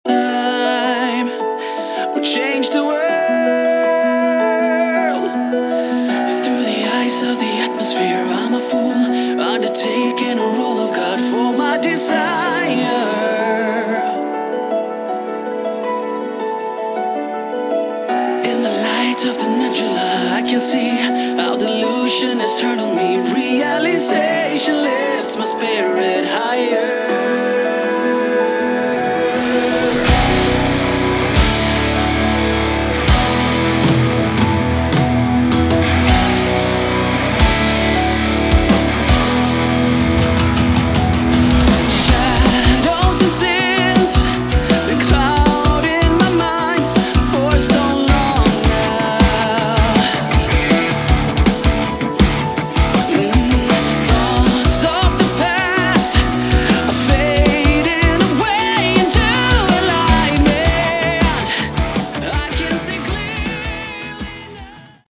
Category: Melodic Metal / Prog Metal
Guitars
Drums
Bass
Keyboards
Vocals